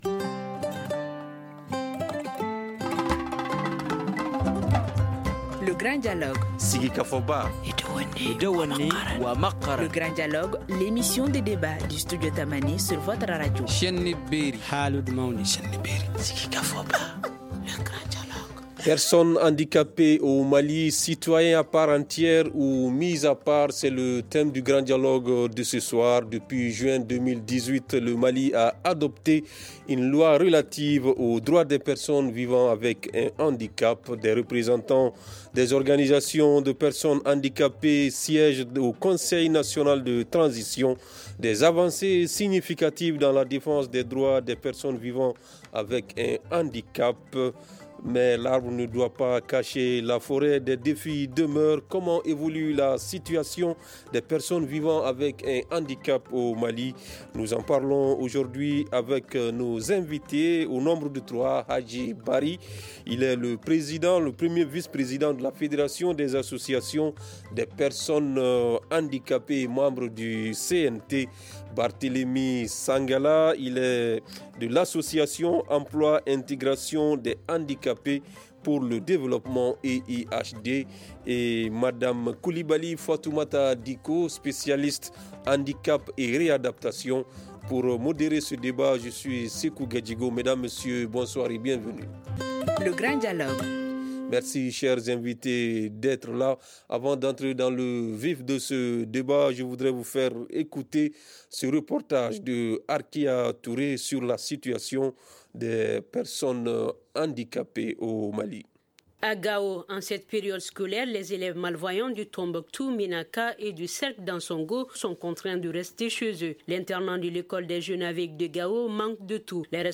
Nous en parlons aujourd’hui avec nos invités :